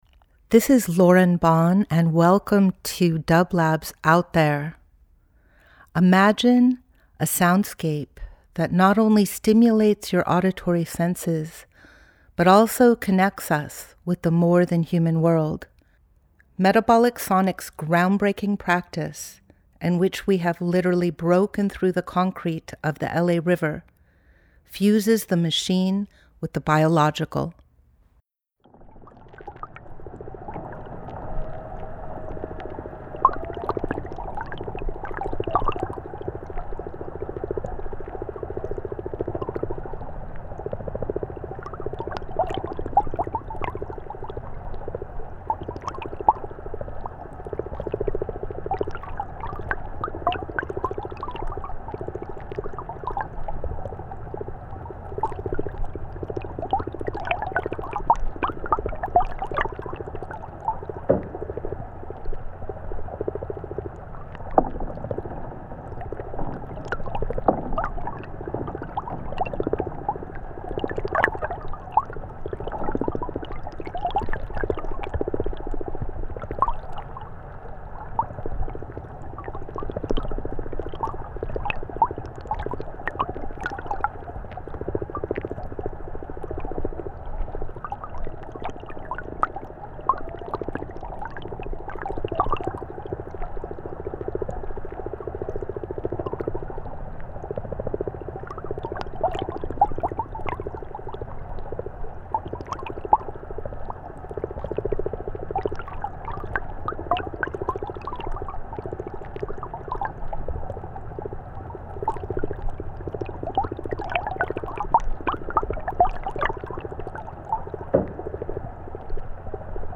Out There ~ a Field Recording Program
Each week we present a long-form field recording that will transport you through the power of sound.
Today we are sharing field recordings from Bending the River’s first phase of construction in the Los Angeles River in autumn of 2019. We will hear various construction sounds inside the concrete jacket of the LA River, many recorded underwater to preserve the vantage point of the river itself.